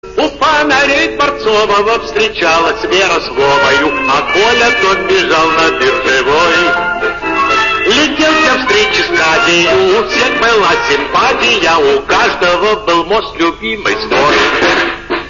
фрагмент песни
пел именно он.